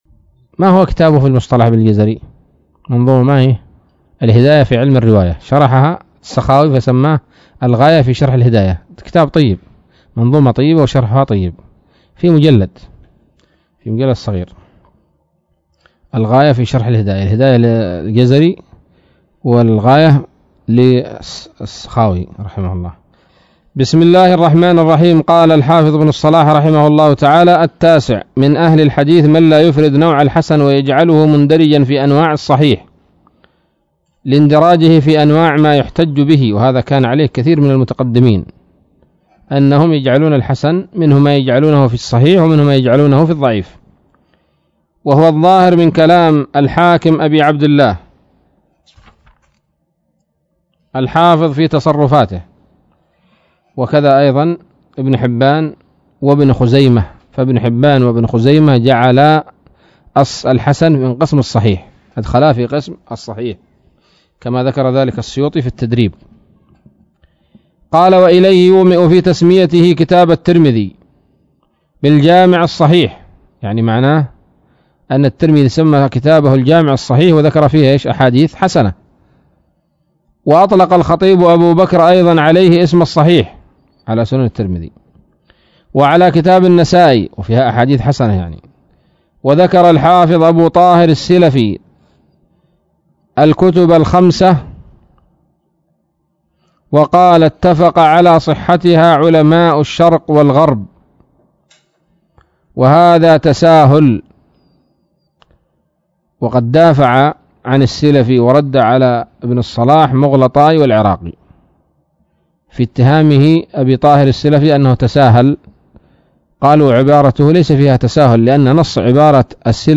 الدرس الثامن عشر من مقدمة ابن الصلاح رحمه الله تعالى